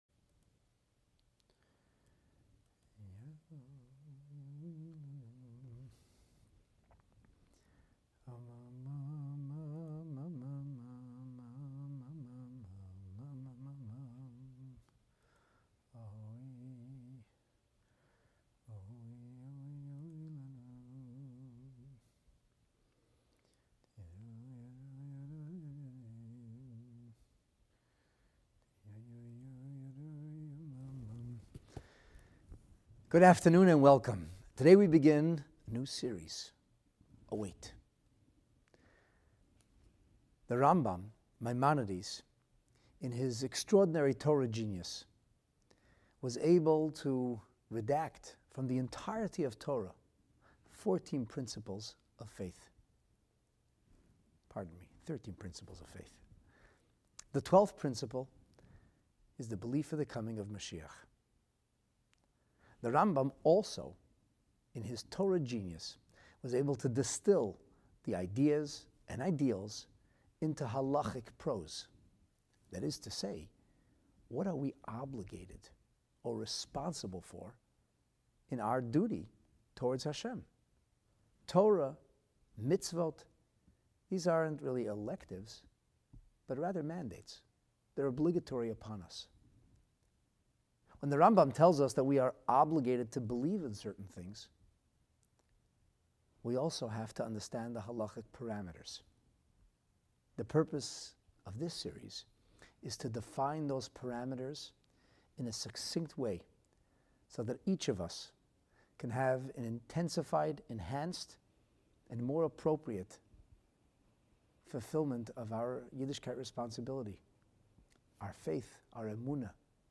We open with MOSHIACH: MAN, OR MOMENT? to establish the foundation, sources and definitions of what precisely we are meant to believe in. This class is the first in an ongoing study series on The Geulah, our final Redemption generally referred to as the Coming of Moshiach.